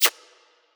pause-hover.wav